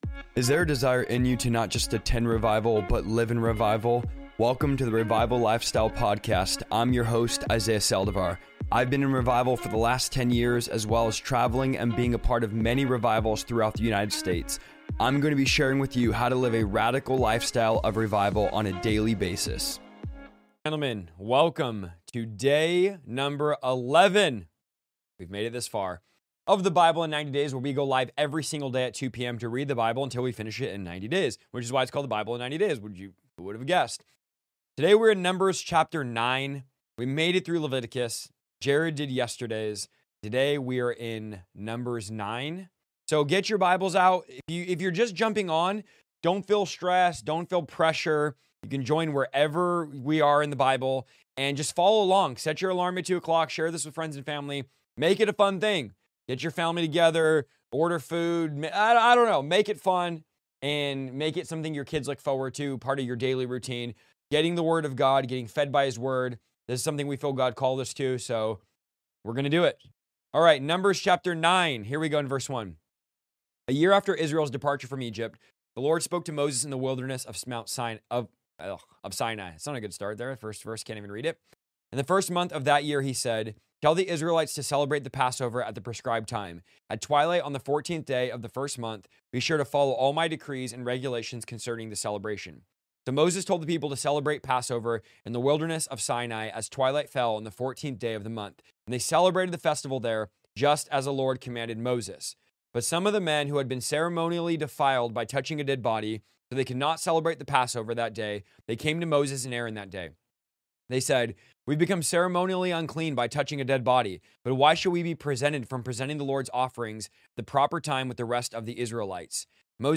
I am going to be LIVE everyday at 2 PM for 90 days straight reading through the entire Bible!